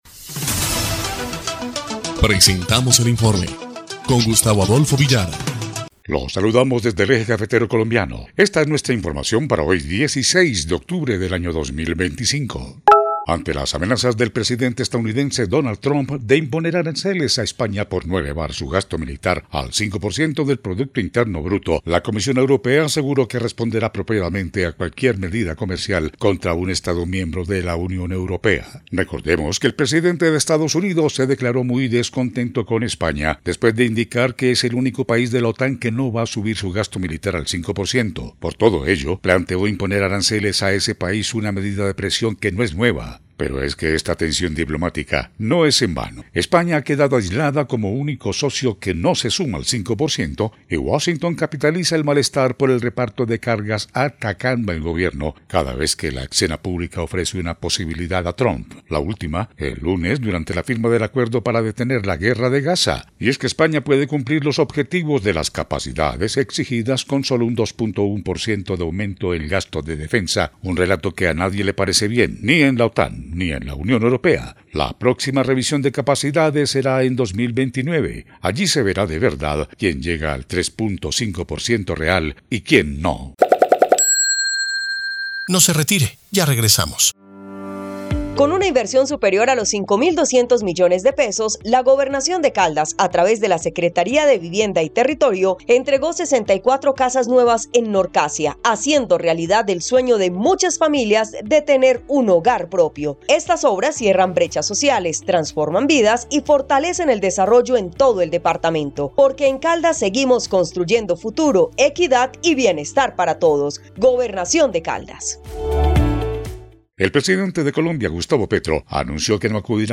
EL INFORME 2° Clip de Noticias del 16 de octubre de 2025